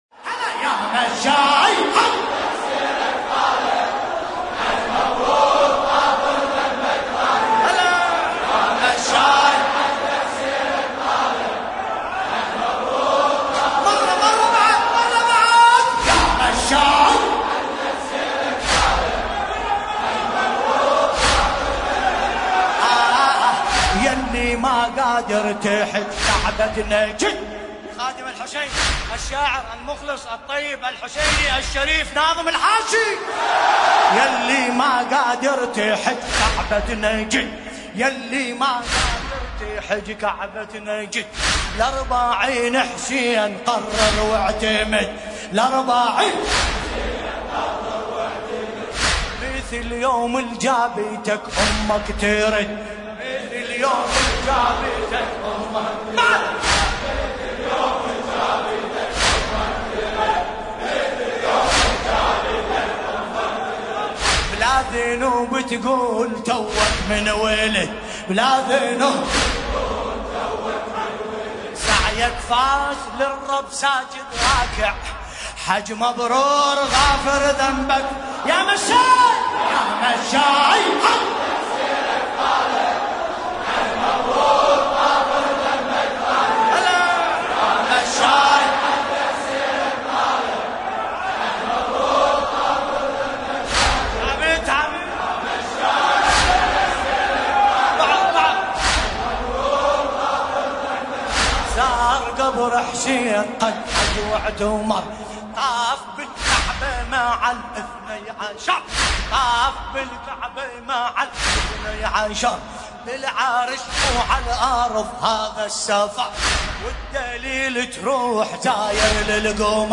ملف صوتی يا مشاي بصوت باسم الكربلائي
قصيدة : يا مشاي للشاعر : ناظم الحاشي المناسبة : ليلة 20 محرم 1441 هـ